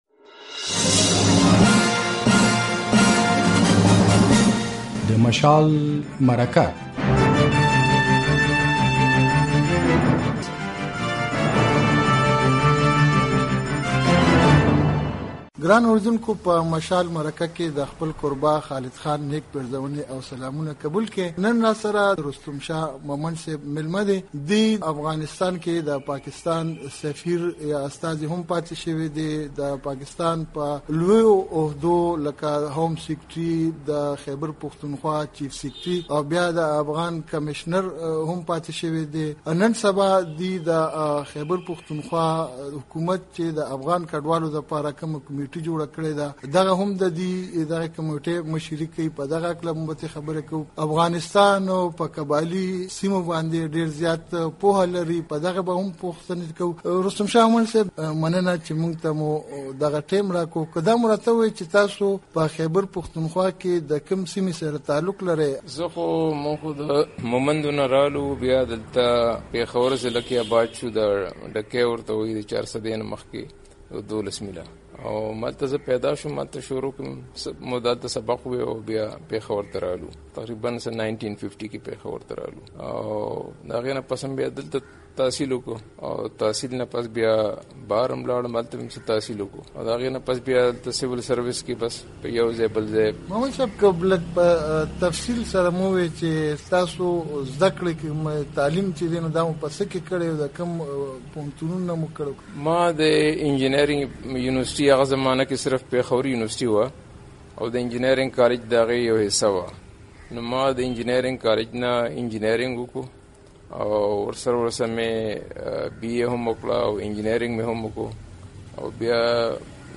د افغانستان لپاره د پاکستان پخوانی سفیر او د خېبر پښتونخوا د حکومت لخوا ټاکل شوی د کډوالو د یوې کمیټۍ مشر رستم شا مومند وايي، په پاکستان کې اوسیدونکي ټول افغان کډوال قانوني دي، هېڅوک د دوی د ځورولو حق نه لري. نوموړي مشال راډیو ته په یوې ځانګړې مرکې کې وویل چې افغان کډوال د پاکستان په اقتصادي پياوړتیا کې رول لوبوي او هېچا ته ترې تاوان نه رسیږي نو د دوی ځورول او په زوره ویستل دې بند کړل شي.